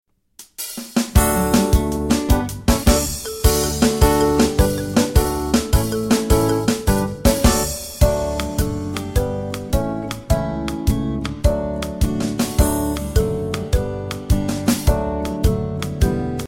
Listen to a sample of the instrumental version.